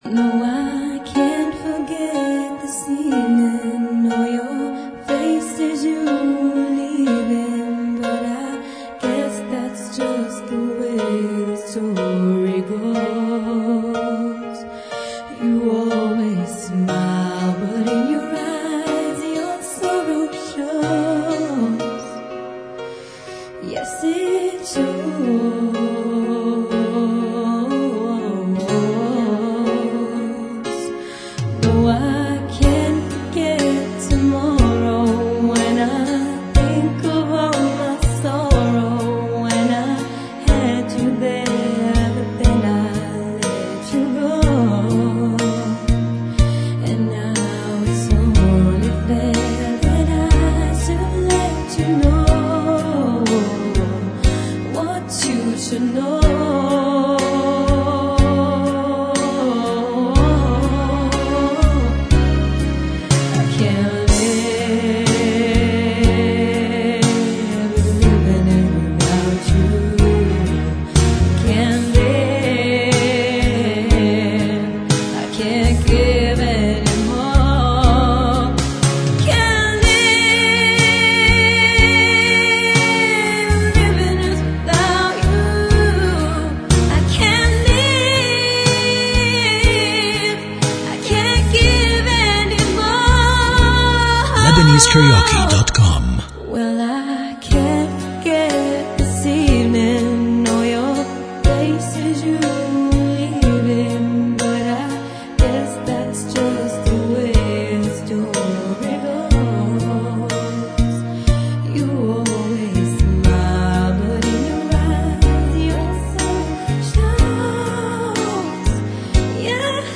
English Karaoke Talented Singers